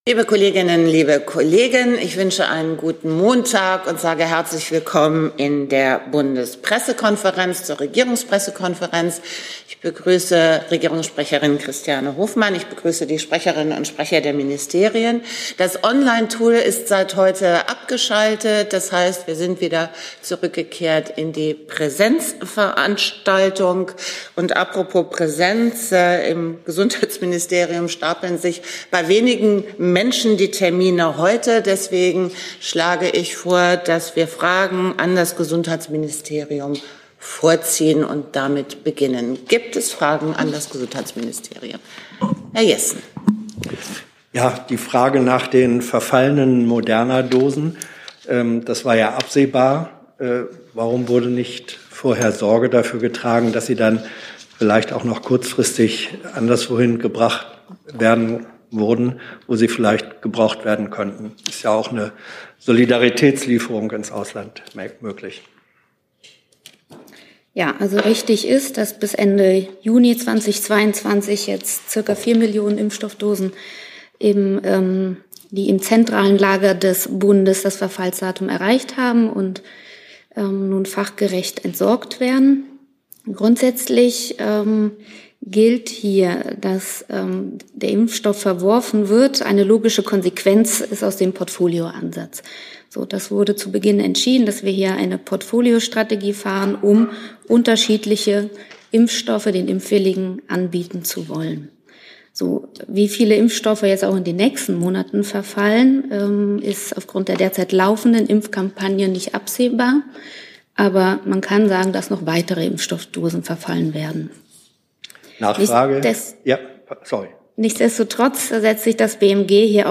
Regierungspressekonferenz in der BPK vom 8. November 2023